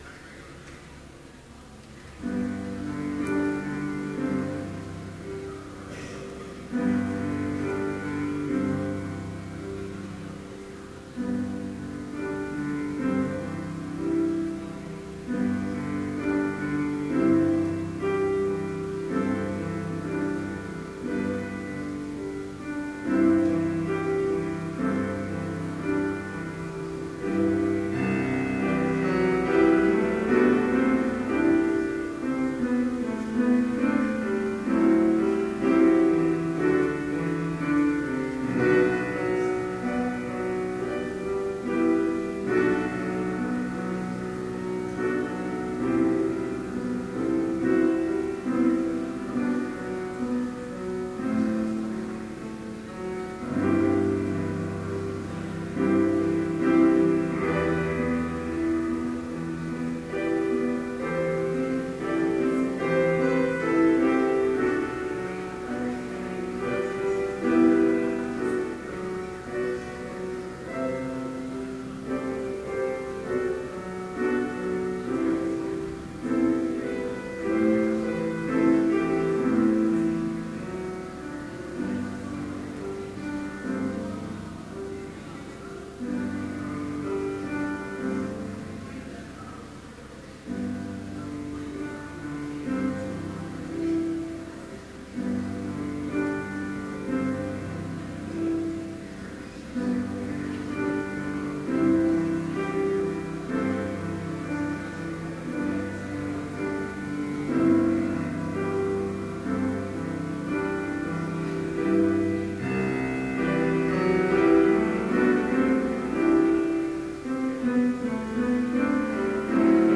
Complete service audio for Chapel - November 2, 2012